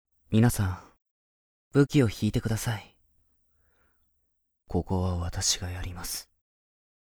【サンプルセリフ】
雰囲気的には朴訥な感じなのにきりっとした敬語という、少し不思議なタイプになりました。